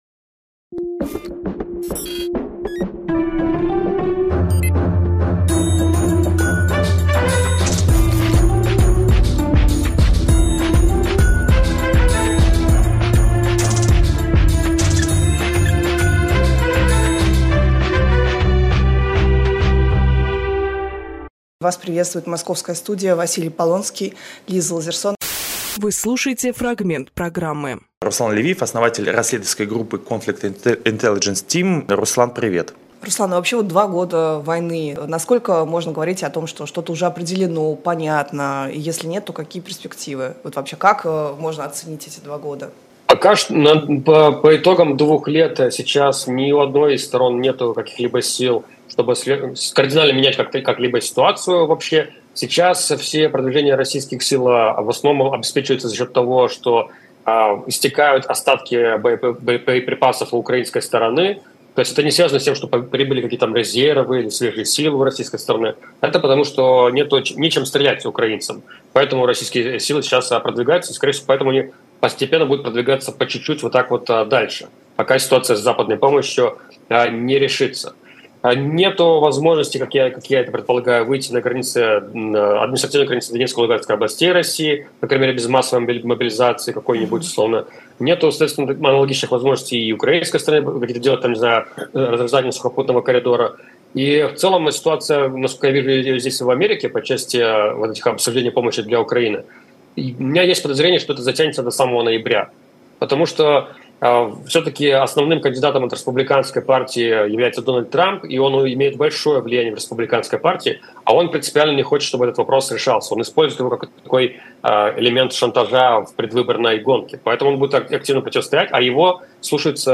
Фрагмент эфира от 25 февраля.